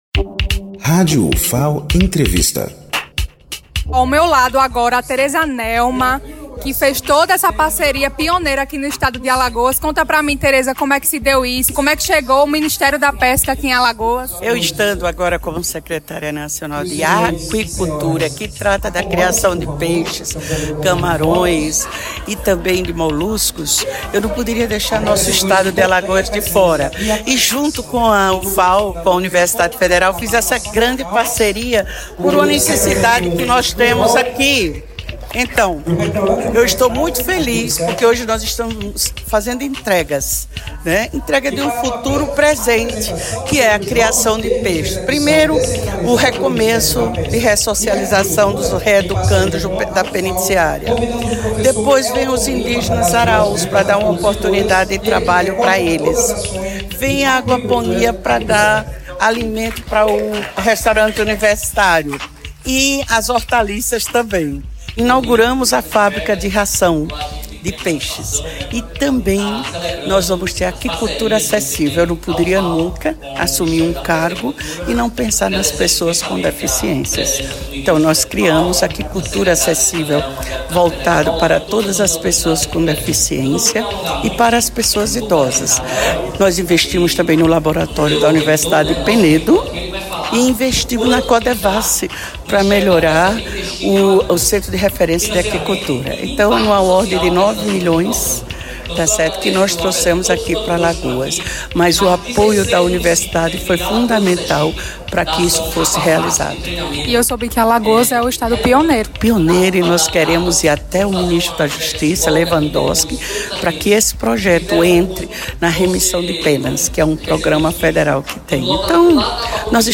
Rádio Ufal - A secretária nacional de Aquicultura, Tereza Nelma, participou da solenidade no Ceca